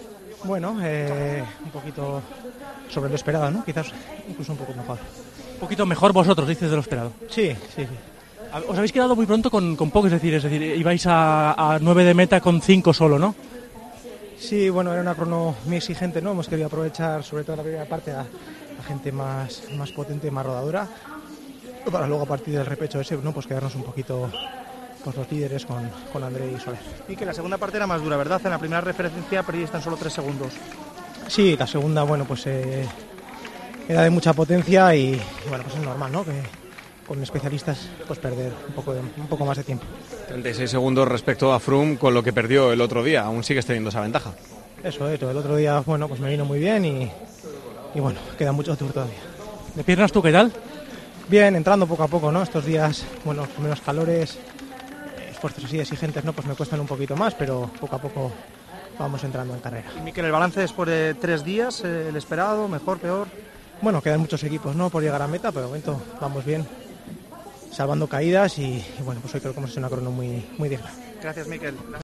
El ciclista del Movistar habló con los medios después de la llegada de su equipo a la meta después de la contrarreloj correspondiente a la 3ª etapa del Tour: "Era una crono muy exigente. La segunda parte era de mucha potencia y es normal perder tiempo contra los especialistas. Queda mucho Tour todavía. Poco a poco vamos entrando en carrera".